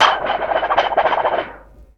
SLAP_VIENTO_WAV.wav